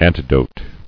[an·ti·dote]